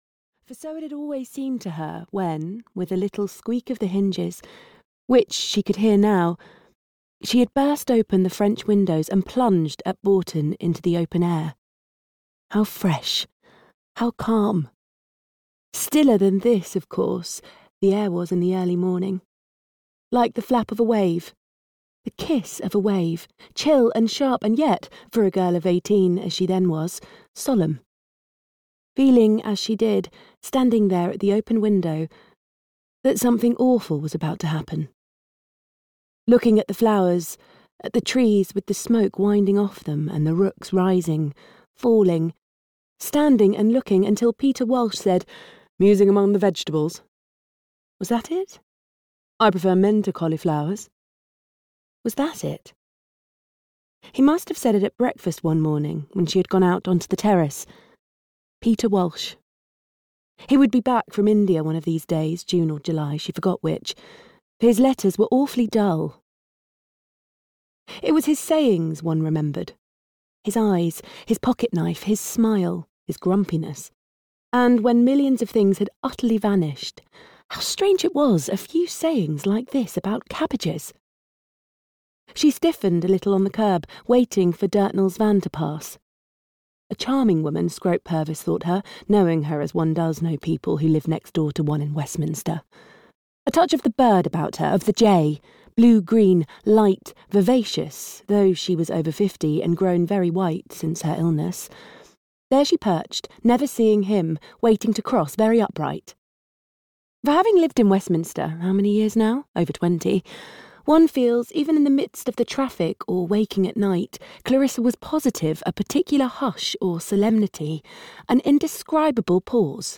Mrs Dalloway (EN) audiokniha
Ukázka z knihy